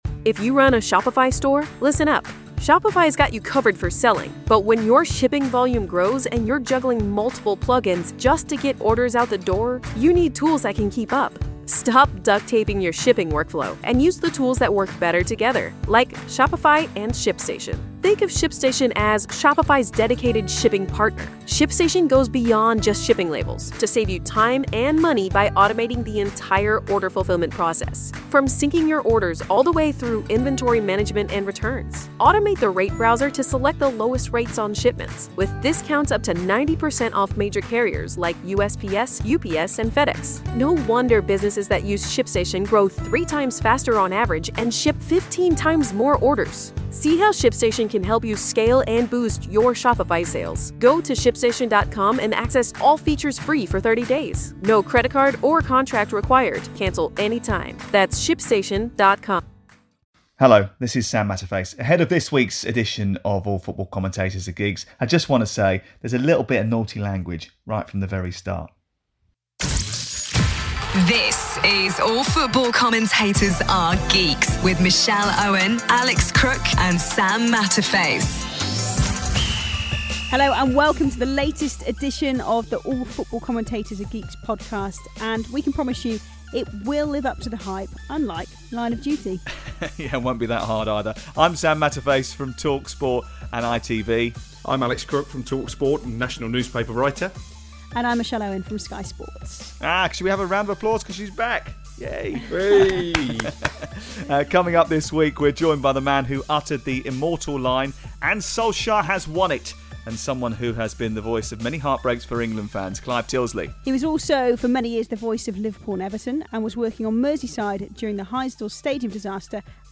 There's a feature length chat with ITV and newly NBC commentator Clive Tyldesley, in which he discusses the disasters of Heysel and Hillsborough, the resignation of Ron Atkinson plus his work with Kick it Out.